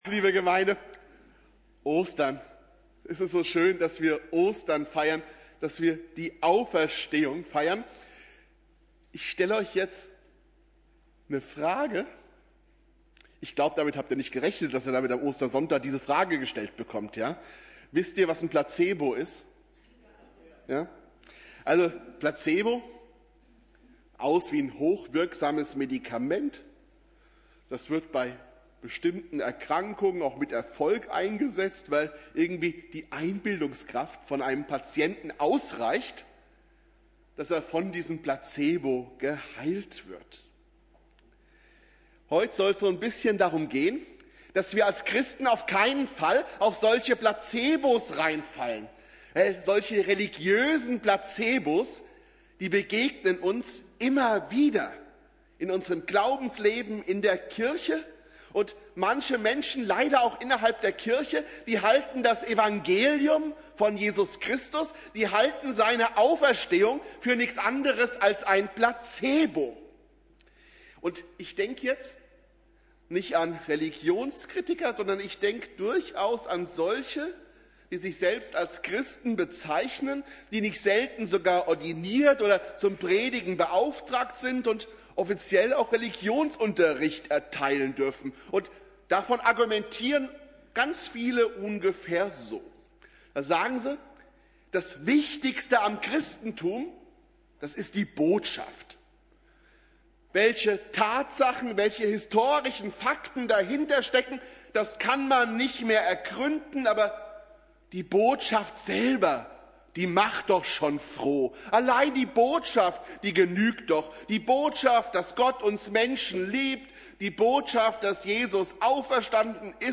Ostersonntag